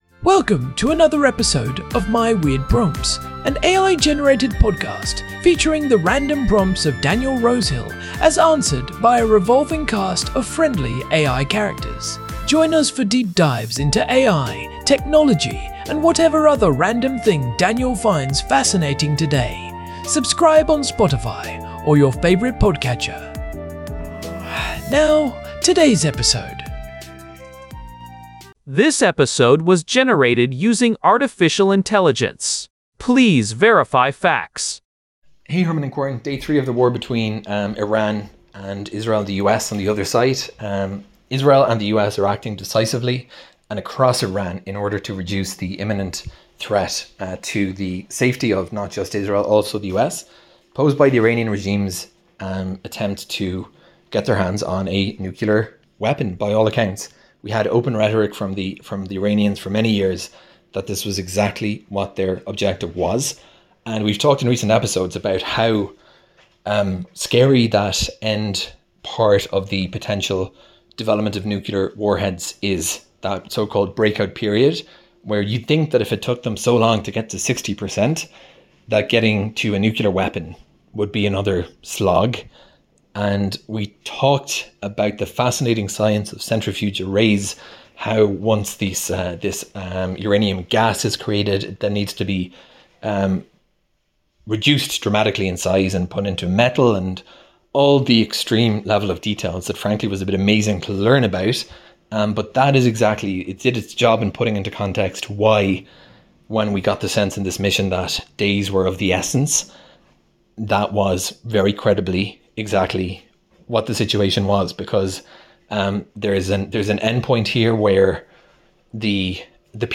Recorded amidst the rumble of Iron Dome intercepts in Jerusalem, this episode of My Weird Prompts tackles a heavy question: Is the United Nations fundamentally unfit for its primary purpose?